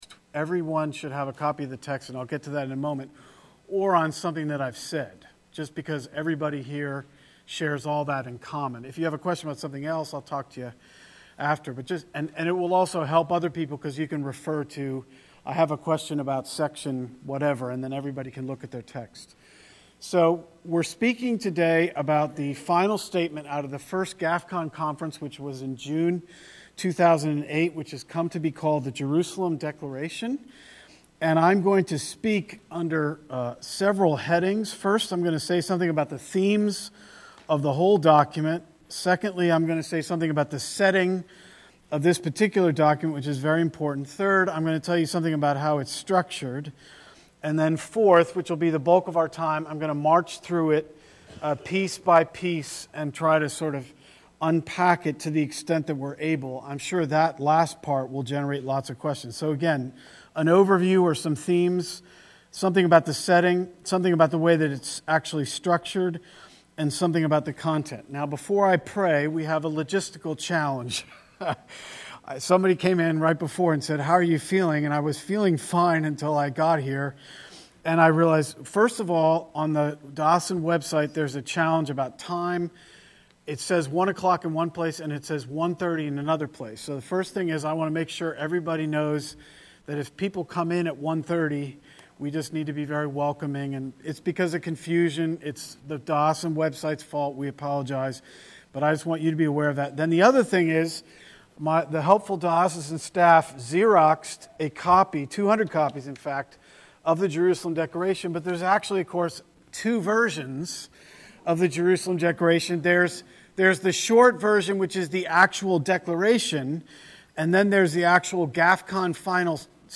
223_conv_wkshp_jersusalem_dec.mp3